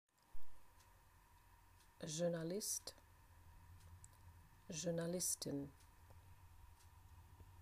Klikom na strelicu čućete izgovor svake reči koja označava profesiju.